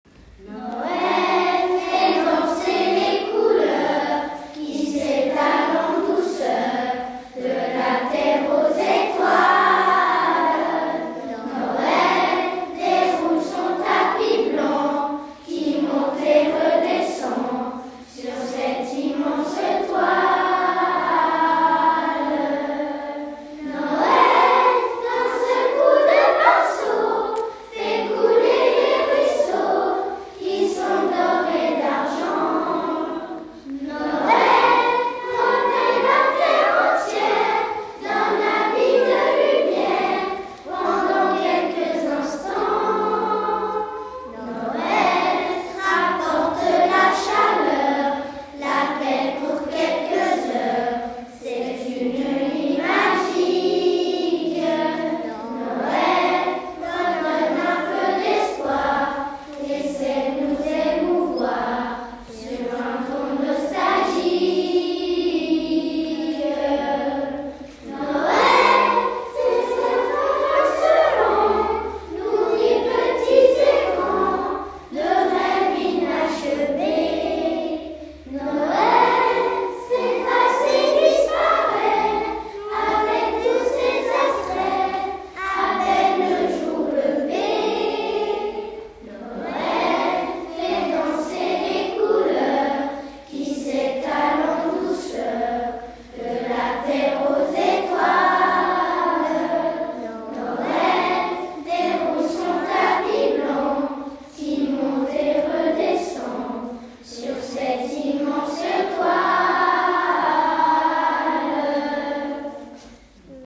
2021-22 : “Chantée de Noël”, les classes de Corcelles
Groupe 2 : classes 1-2P43, 6P42 et 4P42